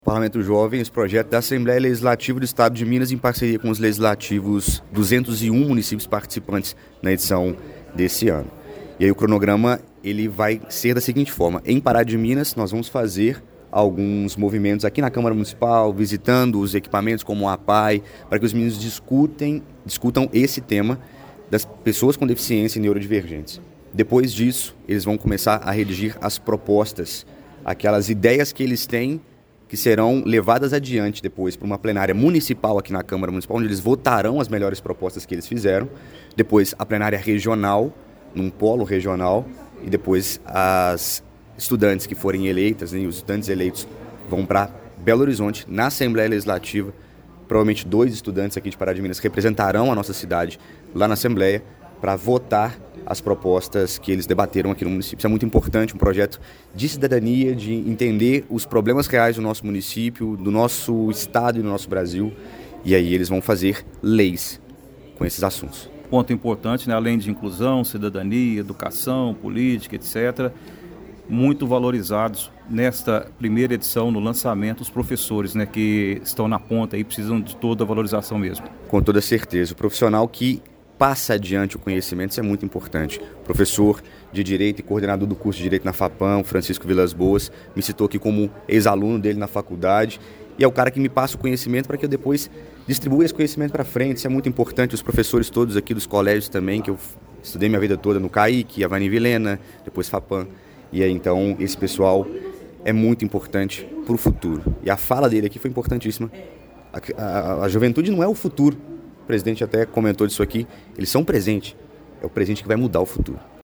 De acordo com o secretário da Mesa Diretora da Câmara de Pará de Minas, vereador Vinícius Alves de Menezes (Republicanos), o cronograma inclui debates nas escolas, elaboração de propostas e uma plenária municipal na Câmara: